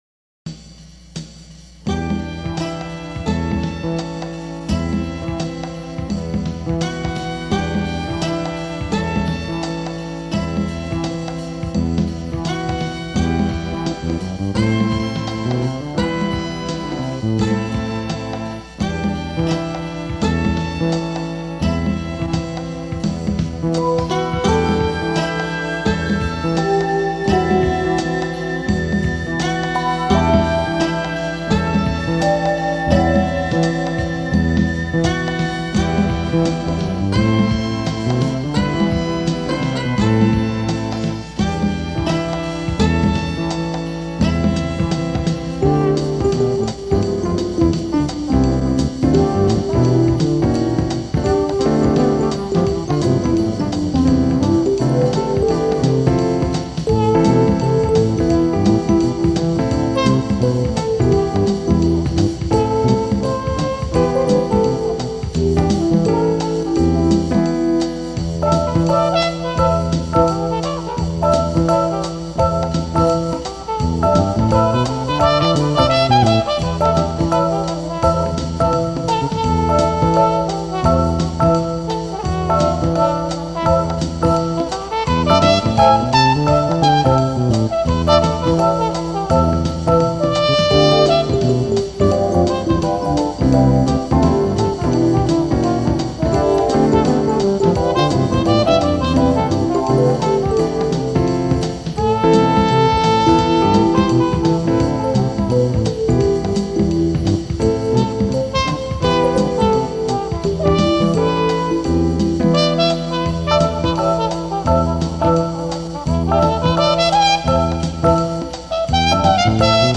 Trumpet, Bass, Keyboard